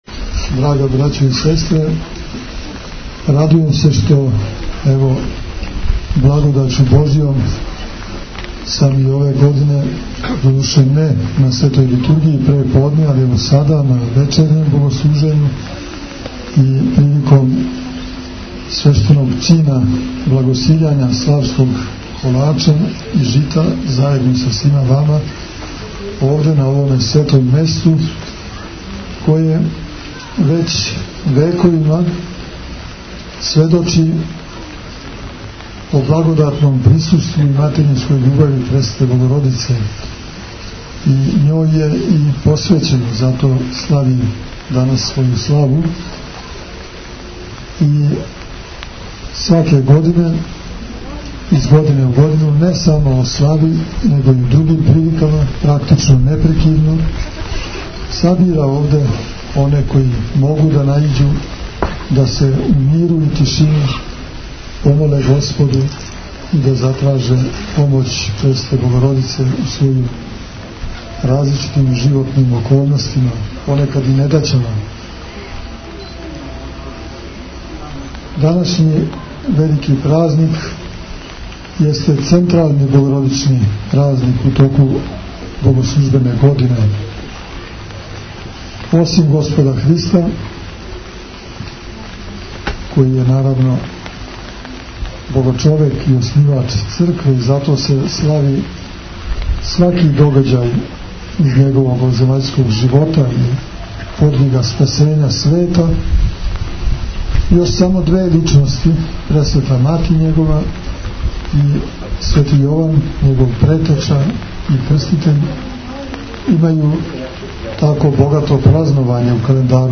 Владика Иринеј је пригодном беседом поздравио сабране честитајући им славу и поучивши их о значају овог великог празника.